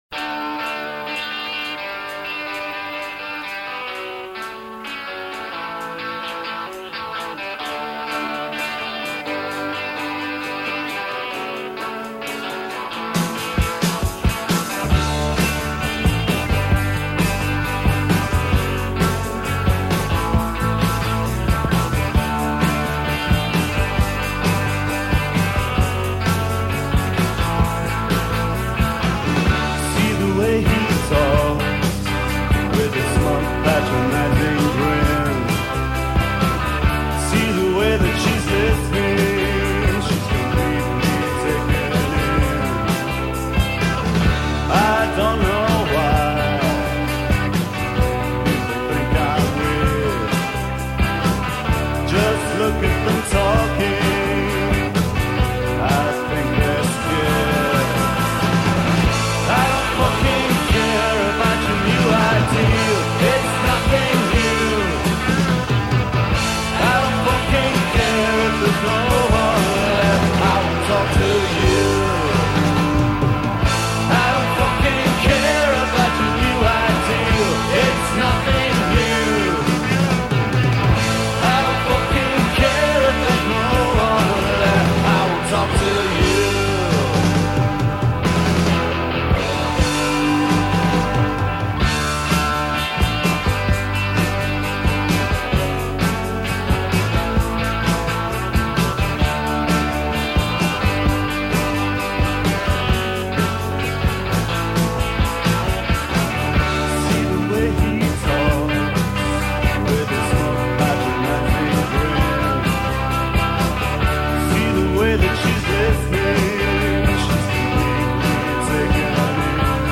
The songs probably come from demo tapes.
Any Hull indie fans remember them?